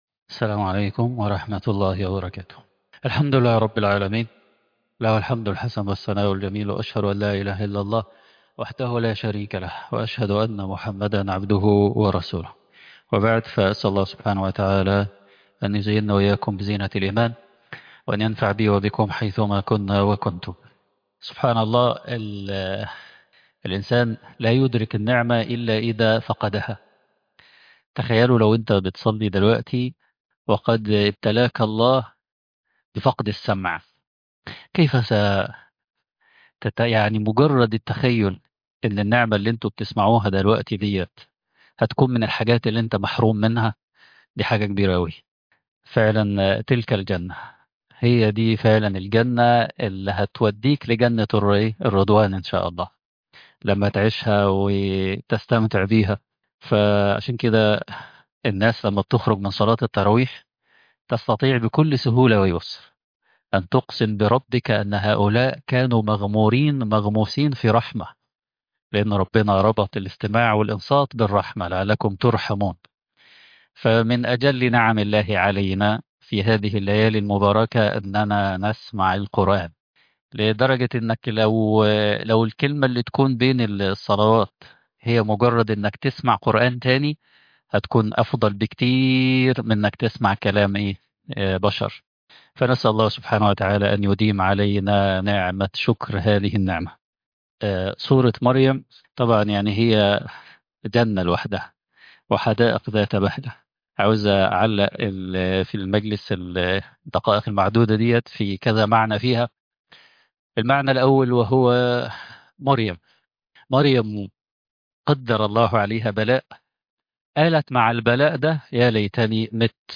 ولم أكن بدعائك رب شقيا _ لقاءات التراويح رمضان 1446هـ